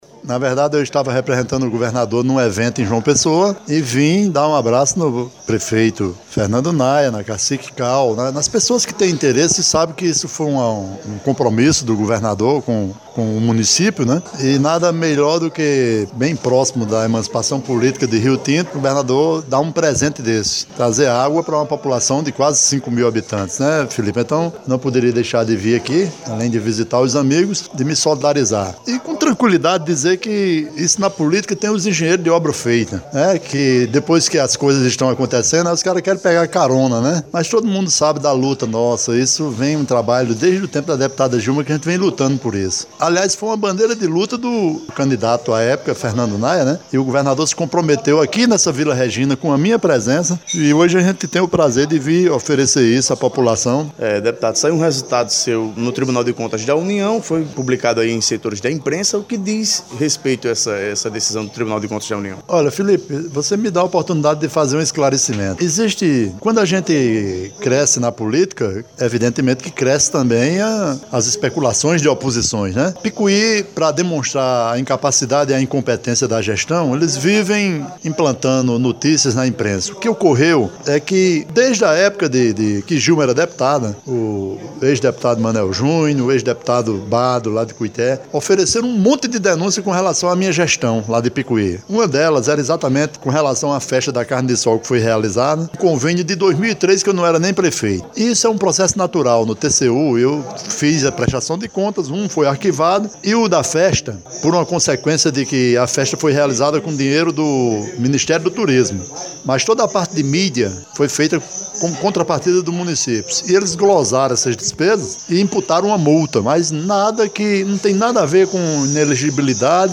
O deputado e secretário de Articulação Municipal do Estado, Buba Germano (PSB), comentou durante entrevista ao Portal PBVale, as notícias veiculadas por opositores, de um acórdão do Tribunal de Contas da União (TCU) que pede a devolução de recursos usados na divulgação do Festival da Carne de Sol do ano de 2008.